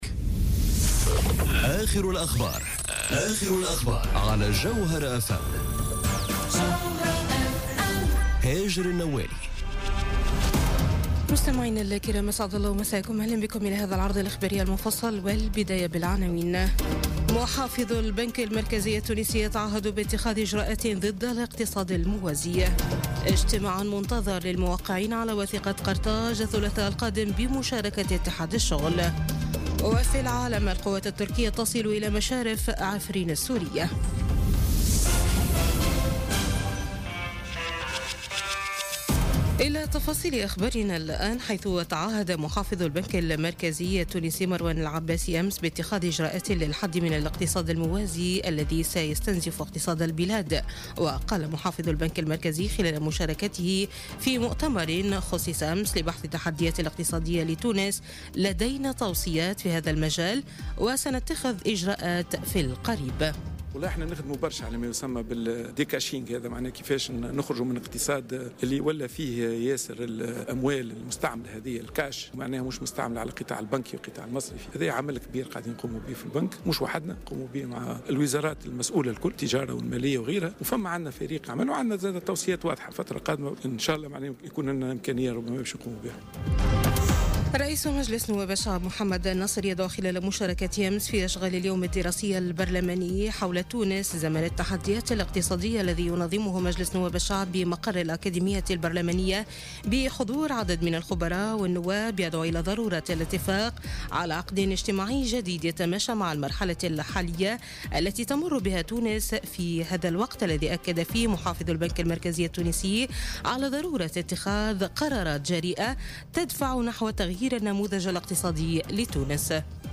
نشرة أخبار منتصف الليل ليوم الاحد 11 مارس 2018